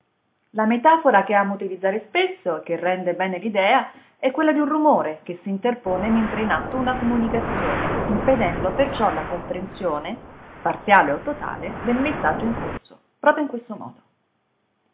Immagina di parlare con una persona e ad un certo punto s’interpone, tra te ed il tuo interlocutore, un rumore.
Hai recepito ciò che stavo comunicando fino all’arrivo dell’interferenza sonora, poi più nulla.
Il rumore acustico che hai ascoltato nel brano è l’equivalente della congestione, del disordine, dell’entropia che prende il sopravvento impedendo l’organizzazione, la comunicazione e la risonanza tra i sistemi.